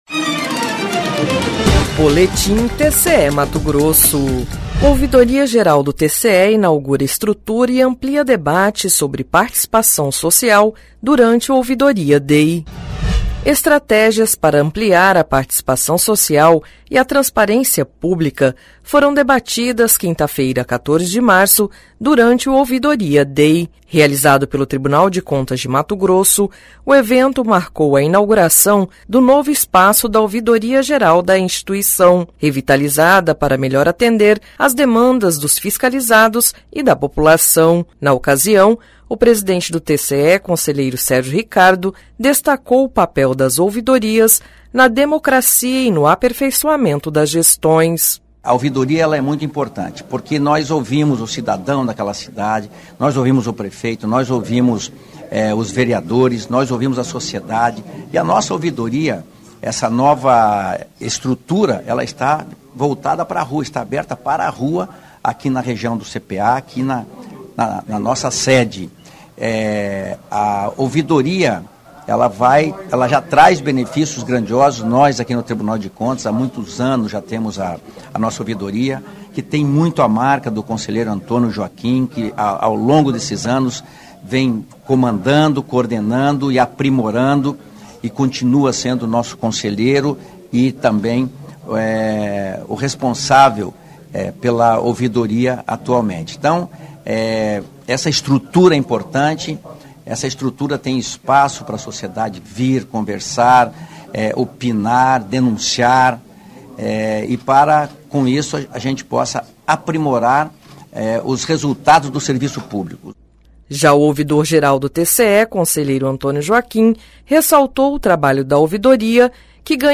Sonora: Sérgio Ricardo – conselheiro presidente do TCE-MT
Sonora: Antonio Joaquim – conselheiro ouvidor-geral do TCE
Sonora: Alisson Carvalho de Alencar - procurador-geral do MPC-MT
Sonora: Daniel Gontijo - coordenador estadual da CGU